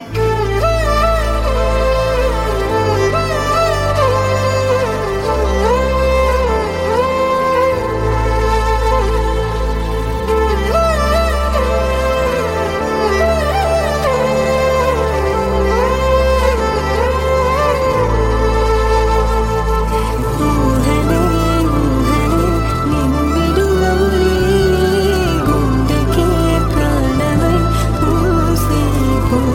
emotional Indian love song
melodious melody
Bollywood